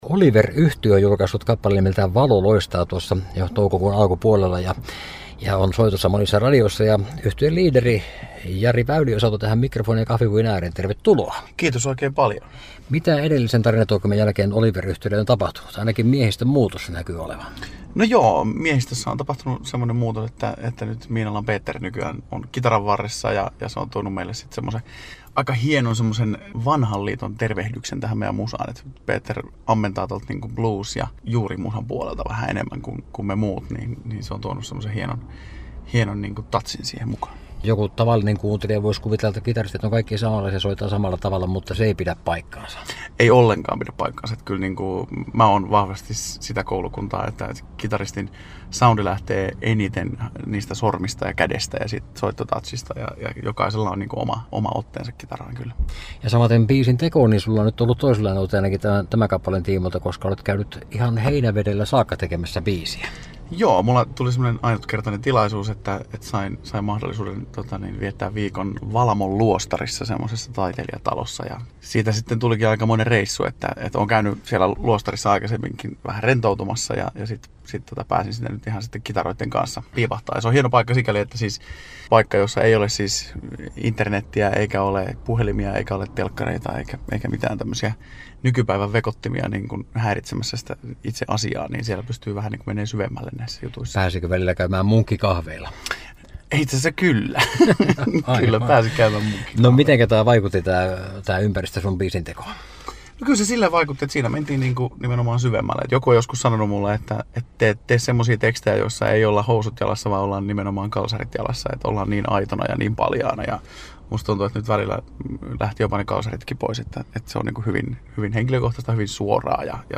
haastattelu